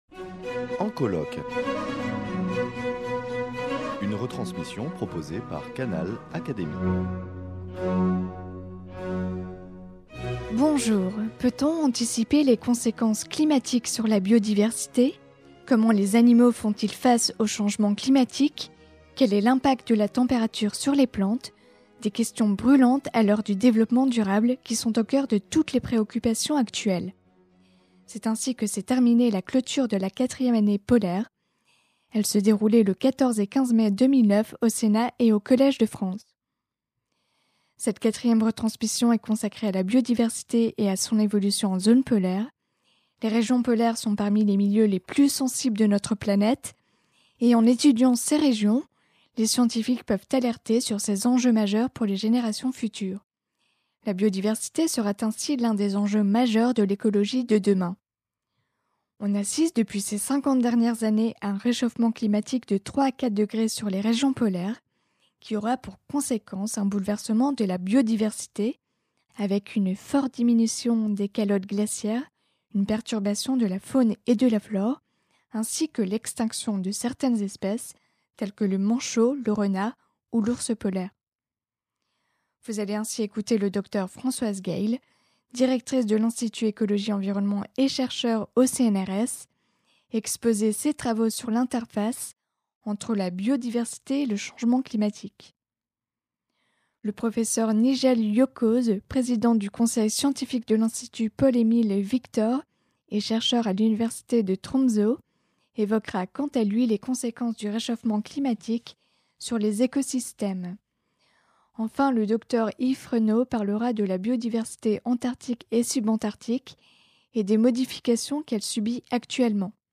Elle se déroulait les 14 et 15 mai 2009 au Sénat et au Collège de France.
Canal Académie vous propose une sélection d’interventions parmi de nombreux invités prestigieux.